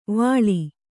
♪ vāḷi